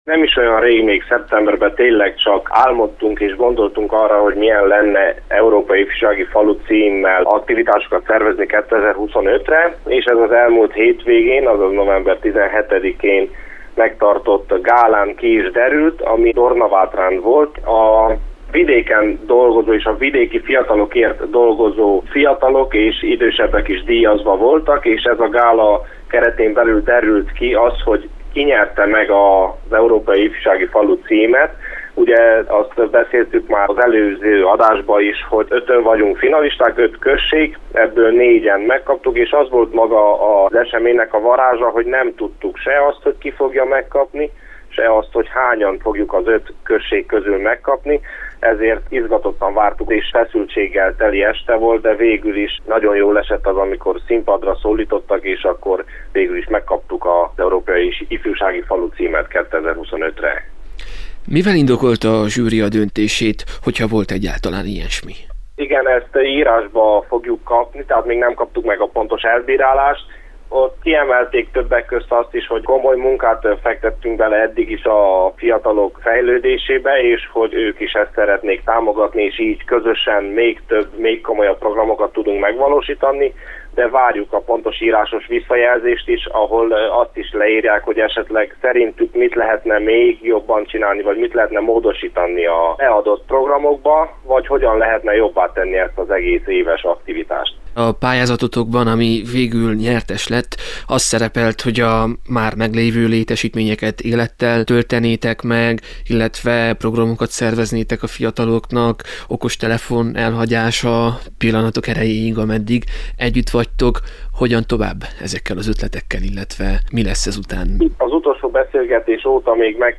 Riporter: